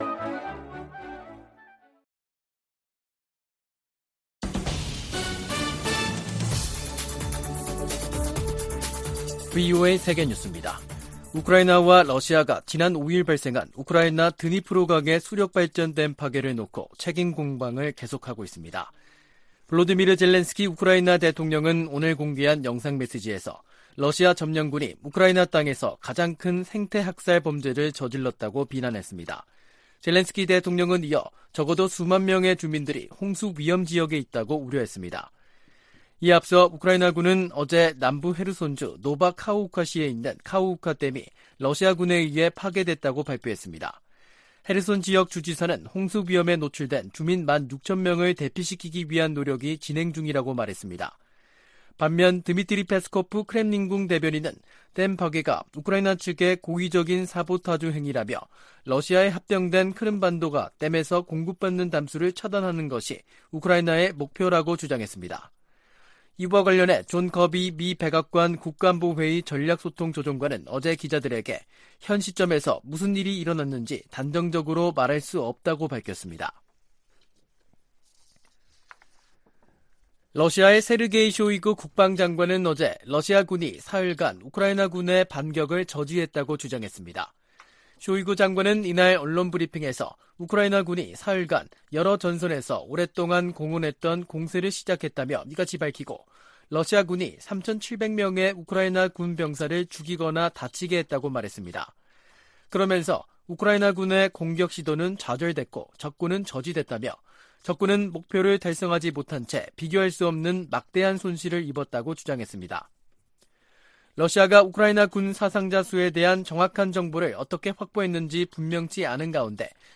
VOA 한국어 간판 뉴스 프로그램 '뉴스 투데이', 2023년 6월 7일 3부 방송입니다. 한국이 유엔 안전보장이사회 비상임이사국으로 다시 선출됐습니다.